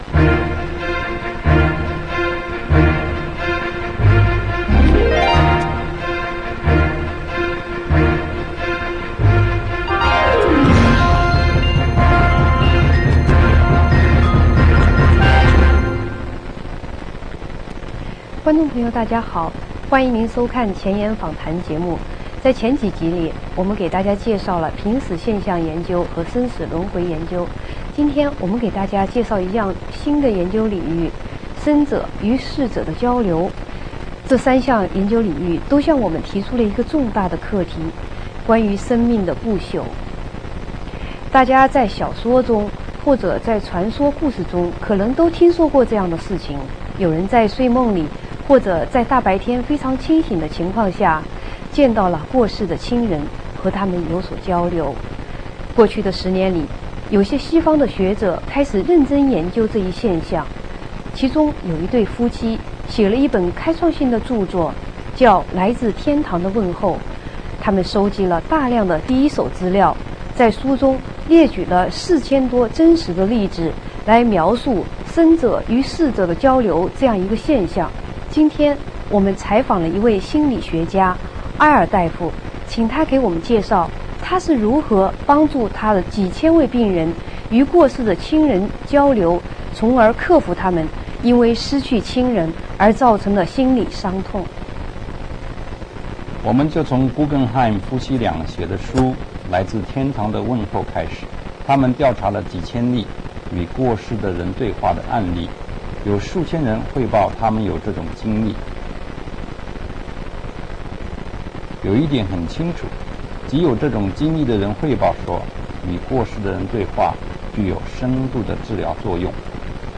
[影音] 前沿訪談--生死契闊